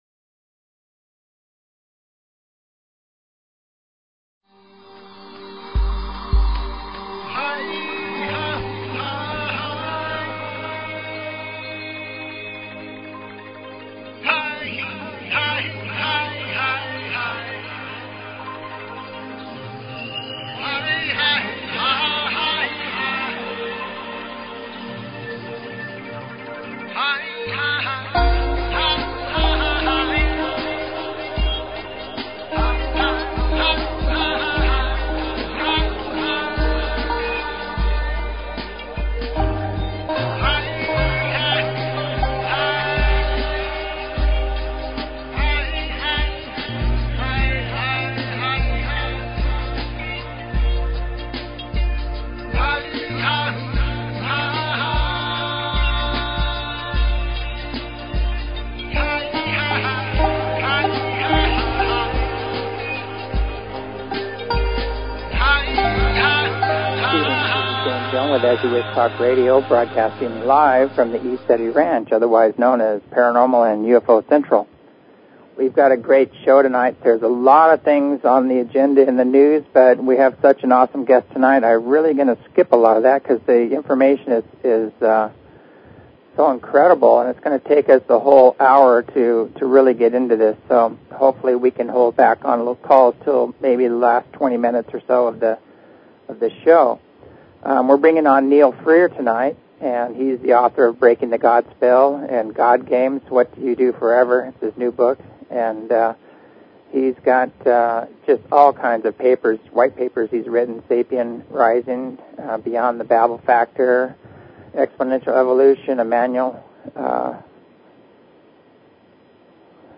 Talk Show Episode, Audio Podcast, As_You_Wish_Talk_Radio and Courtesy of BBS Radio on , show guests , about , categorized as
SATURDAY NIGHT 8:00PM PST LIVE FROM THE RANCH Live Broadcast From ECETI Deep within and undisclosed location somewhere near the base of legendary Mt Adams